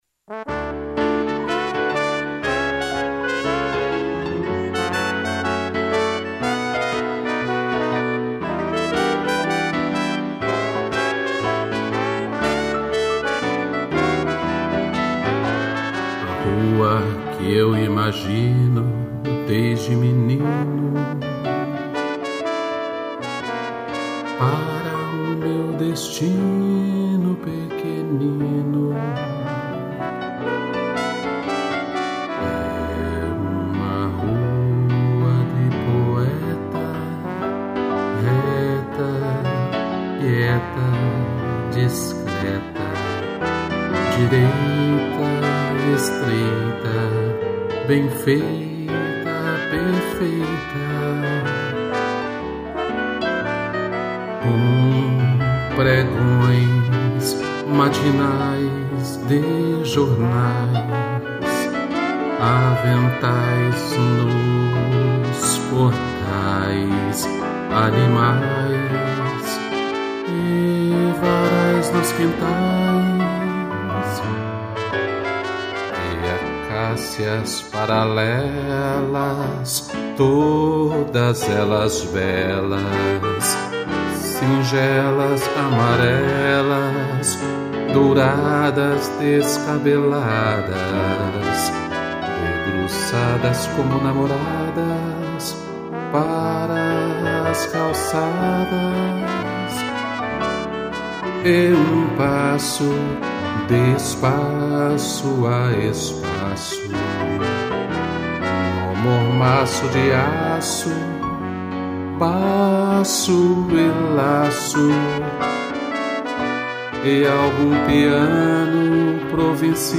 voz
2 pianos, trombone, trompete e clarinete